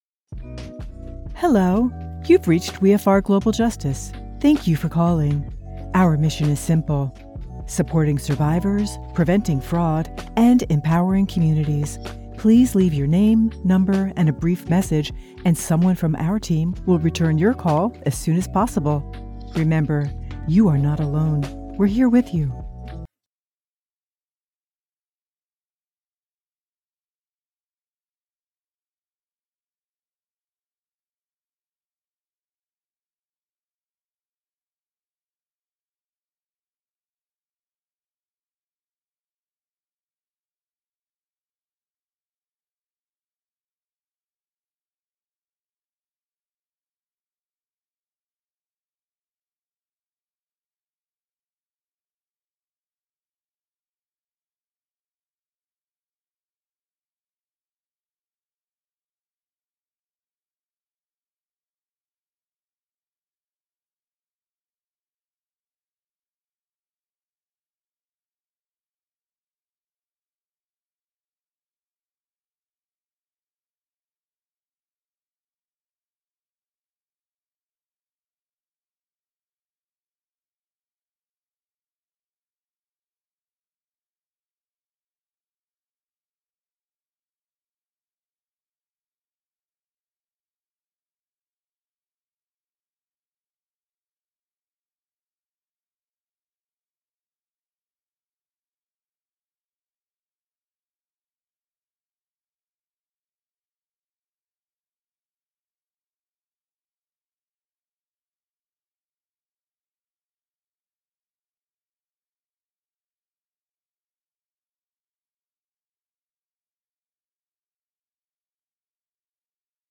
Phone, warm, natural, engaging, conversational, approachable
phone demo_mixdown_Stereo.mp3